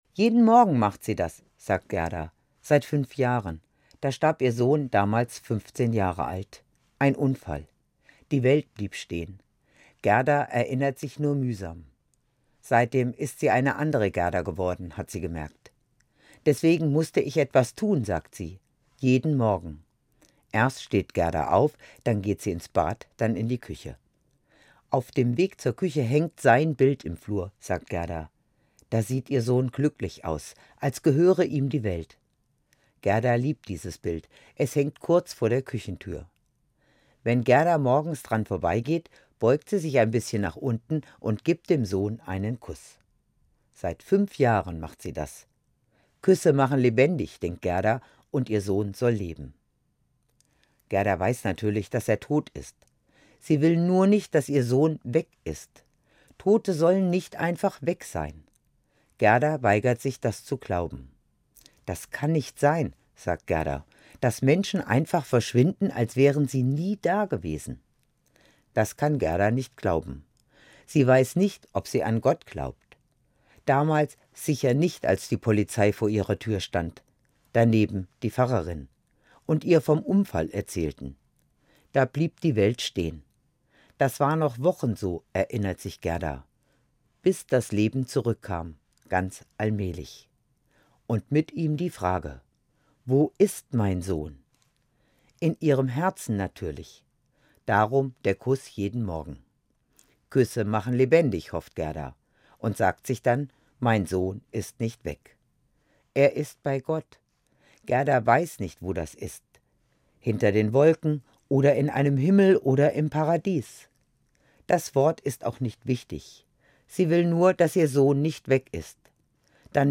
Gesprochen von Pfarrerin